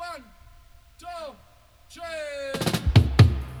134-FILL-FX.wav